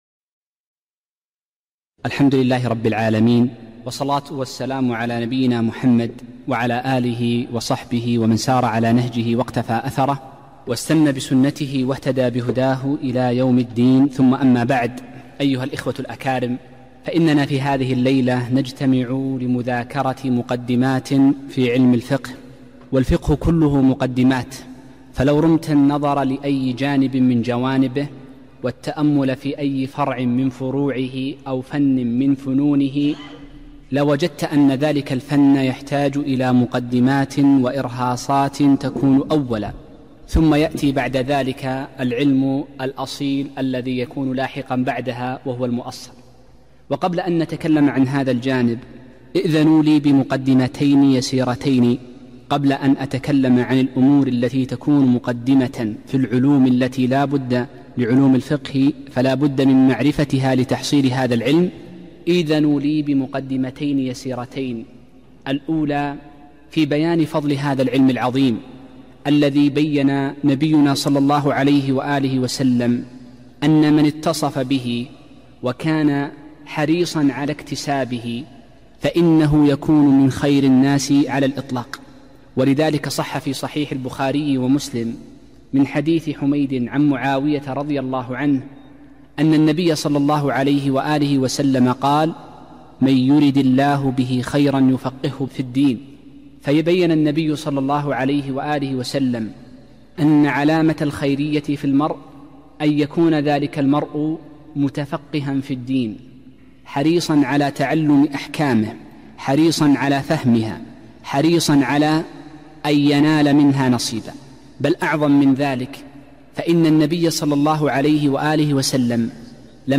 محاضرة - مفاتيح علم الفقه